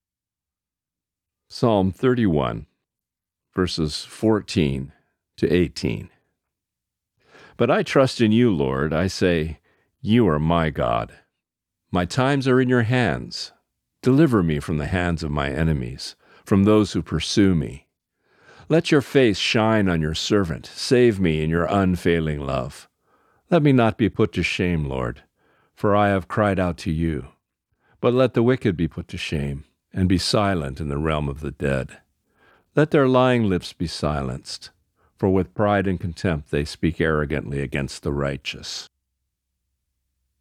Reading: Psalm 31:14-18